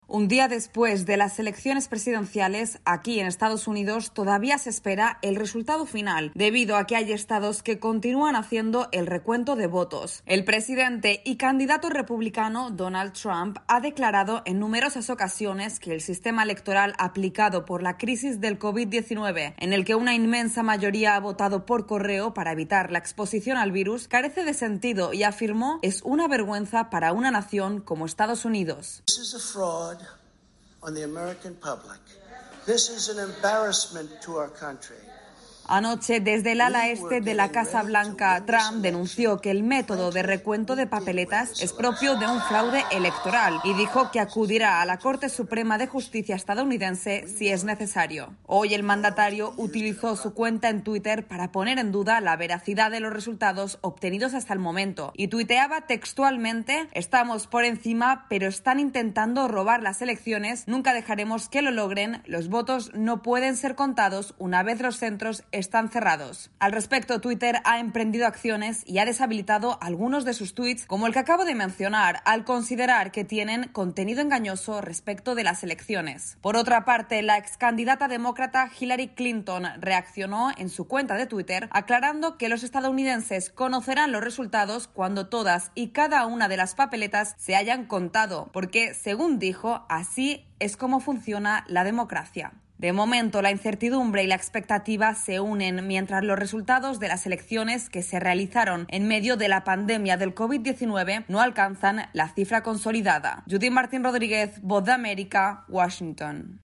AudioNoticias
Donald Trump utiliza su cuenta de Twitter para expresar frustración y enfado con el sistema electoral en los comicios del martes. Informa desde la Voz de América en Washington D.C.